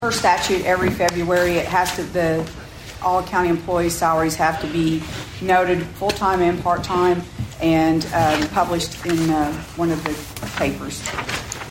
The Board of Osage County Commissioners convened for a regularly scheduled meeting at the fairgrounds on Monday morning.
County Clerk Christina Talburt explains what this is.